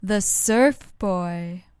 用AT2020话筒录入Apogee Duet.
标签： 美国 诗歌 要求 萨福 讲话 谈话 声音 语音
声道立体声